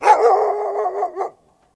Завывающий вой